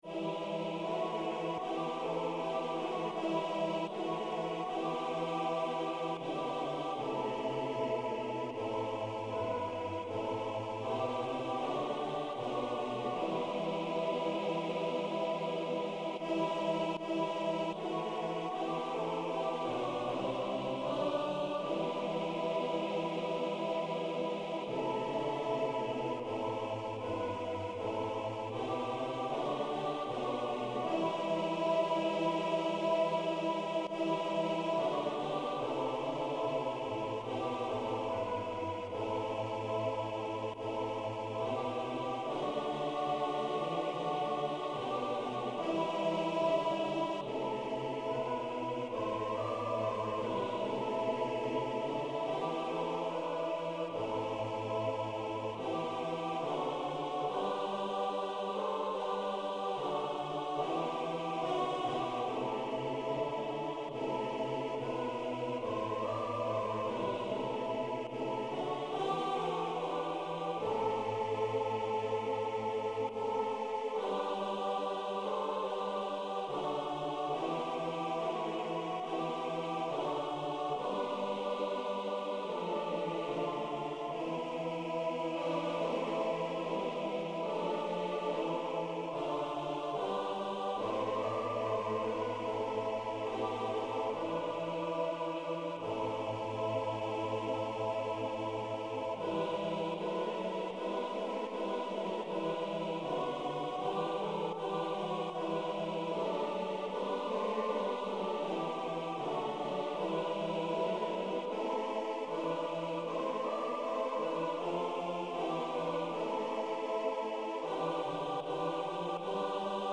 Milost-mira_Trubachev-4gol-Eminor.mp3